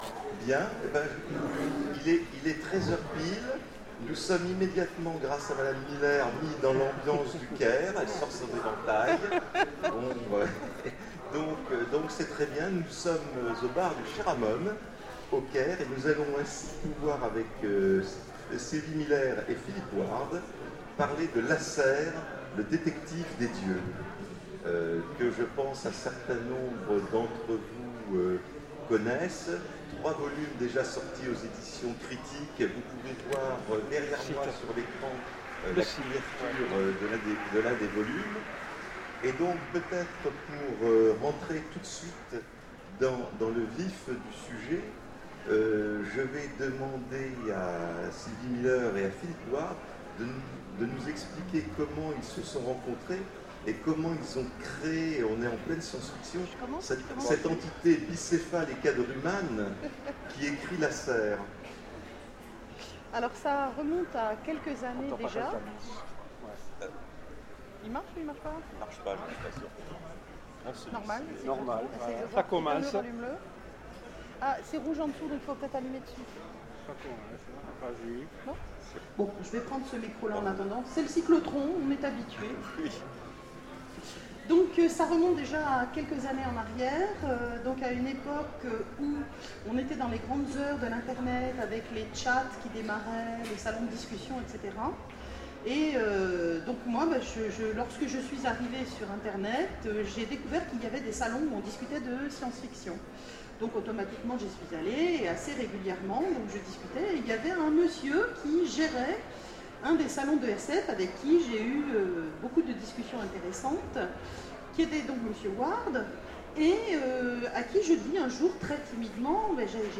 Rencontre avec un auteur Conférence